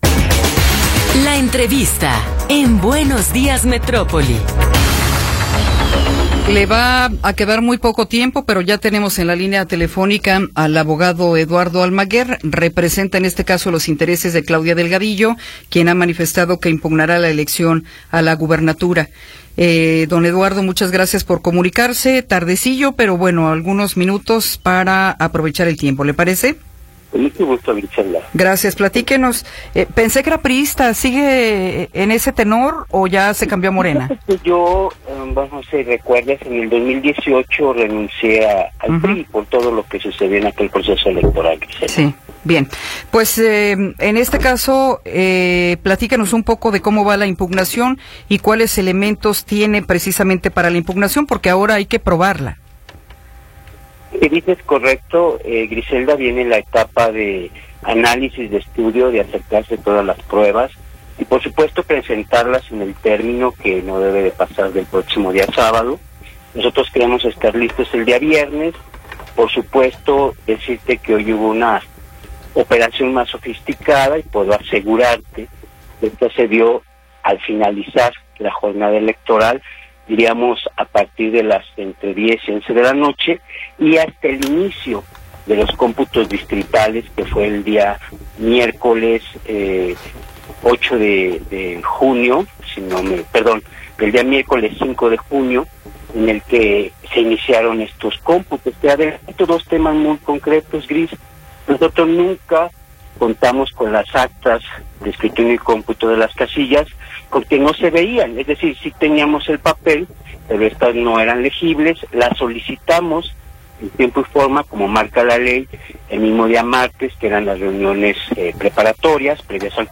Entrevista con Eduardo Almaguer Ramírez
Eduardo Almaguer Ramírez, regidor del ayuntamiento de Guadalajara y ex-fiscal de Jalisco, nos habla sobre la seguridad en el estado.